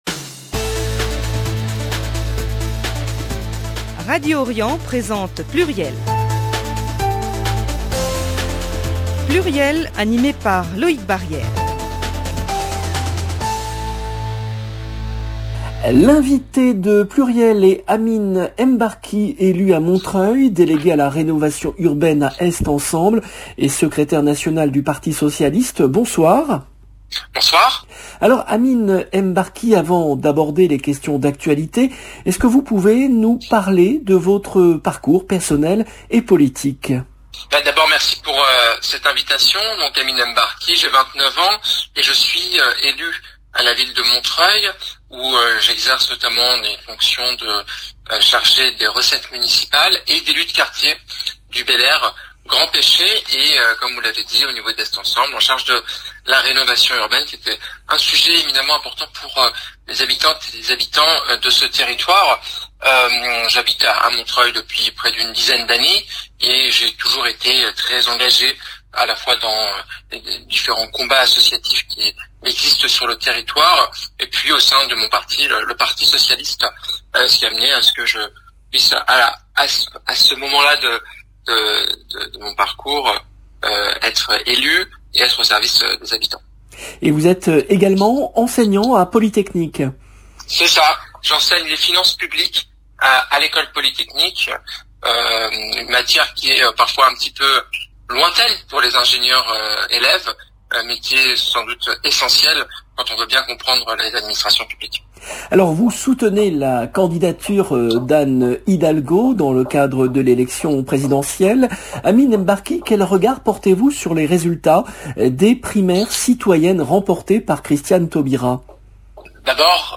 Amin Mbarki, élu de Montreuil et secrétaire national du Parti socialiste
L’invité de PLURIEL est Amin Mbarki, élu à Montreuil, délégué à la Rénovation urbaine à Est Ensemble et secrétaire national du Parti socialiste